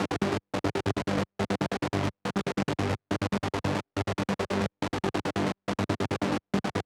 VTS1 Space Of Time Kit Bassline